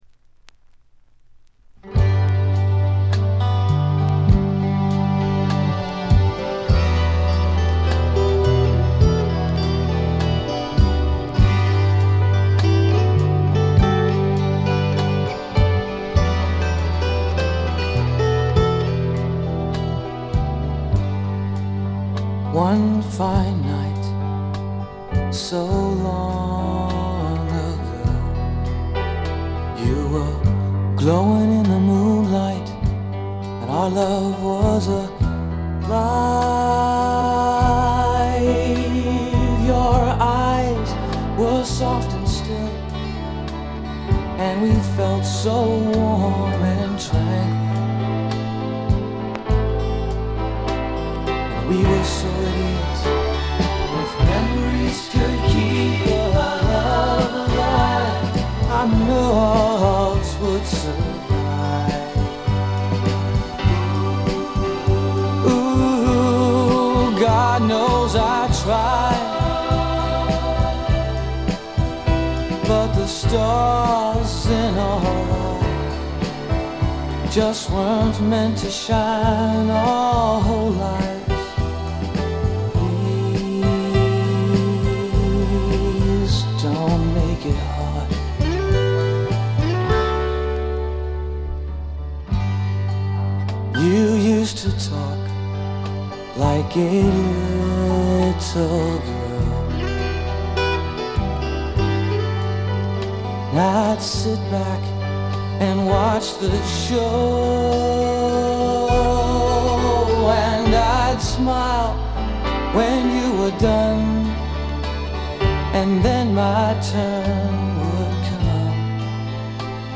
内容はポップでAOR的なサウンドが印象的なシンガー・ソングライター・アルバム。
試聴曲は現品からの取り込み音源です。